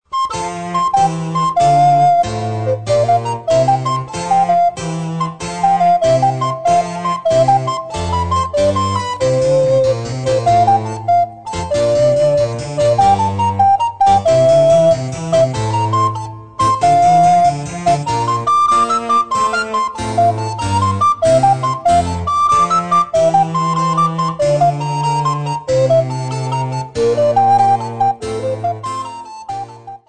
Besetzung: Altblockflöte und Basso continuo